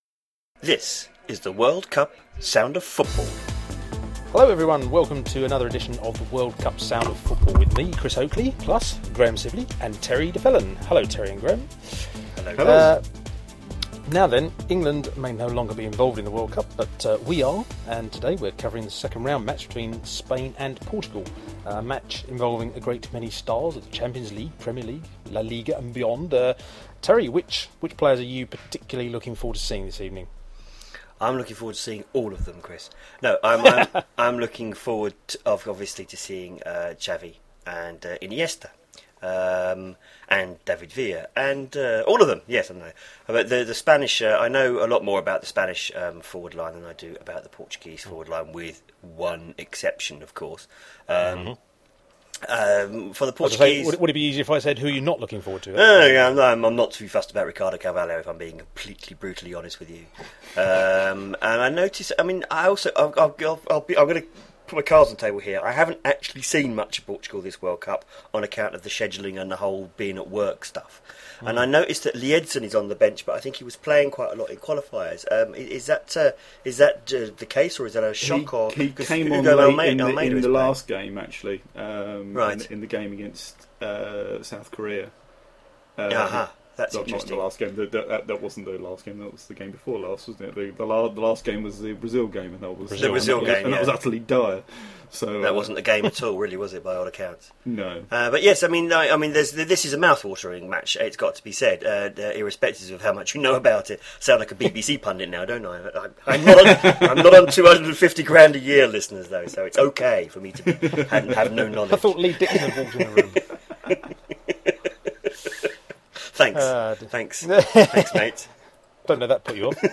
This time the chaps thoroughly enjoy Spain 's 1-0 victory over Portugal . If you're unfamiliar with the format of these podcasts, we record a segment before the game, at half time and just after the final whistle.